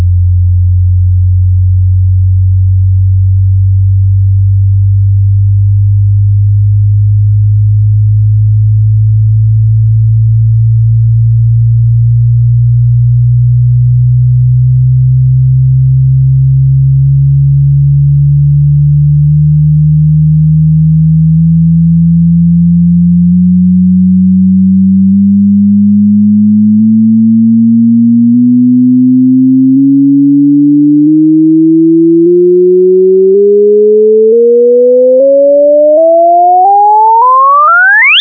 Accordingly, we had to fudge things a bit: In these cases, frequencies are shifted by a factor of a few thousand from the way that nature would actually present them.
Notice that this sounds very jagged: You can hear the moments at which the small body crosses a grid point.